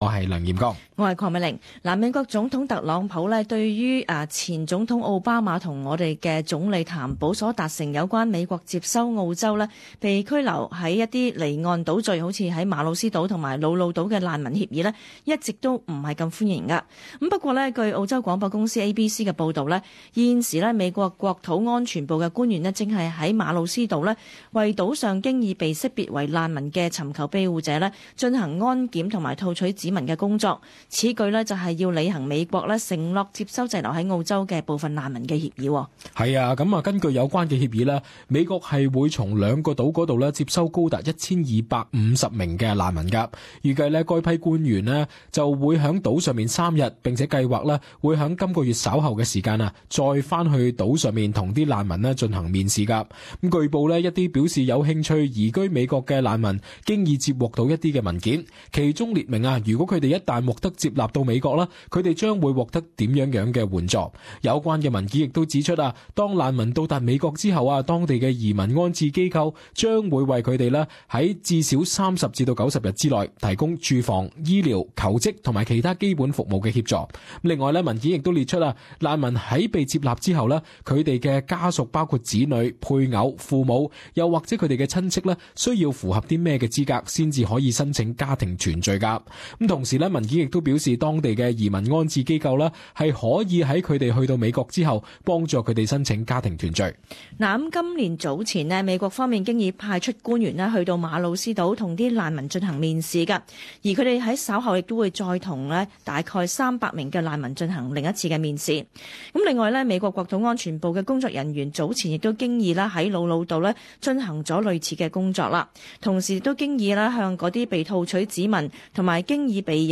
【时事报导】美国官员为马努斯岛难民进行安检